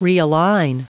Prononciation du mot realign en anglais (fichier audio)
Prononciation du mot : realign